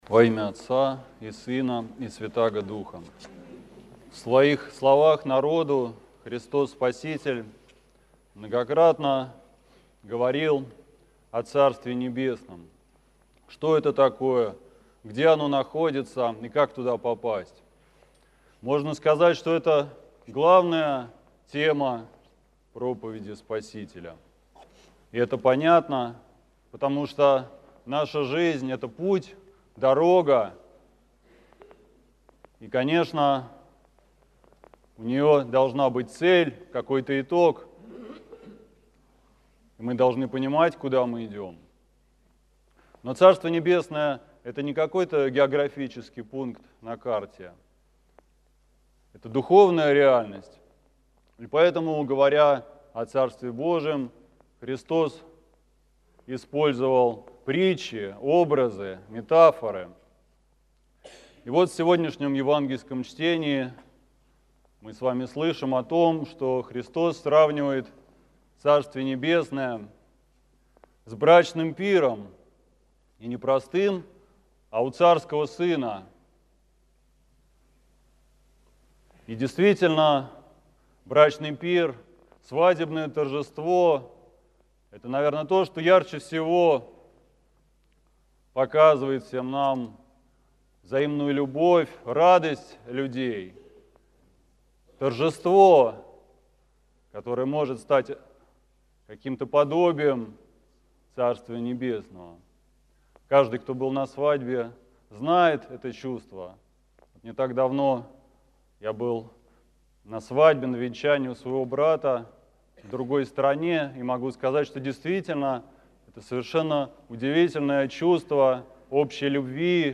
Слово в Неделю 14-ю по Пятидесятнице, по Воздвижении